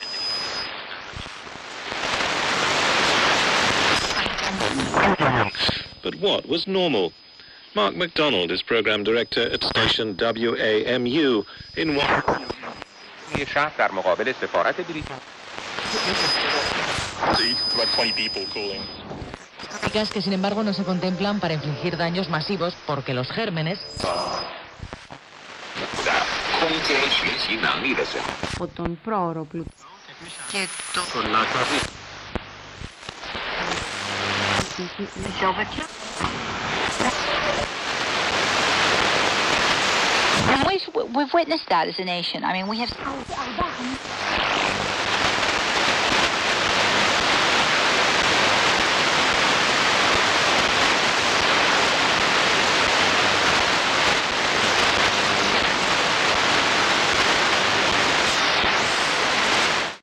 Category 🤣 Funny
circus computer console happy humor humoristic jingle radio sound effect free sound royalty free Funny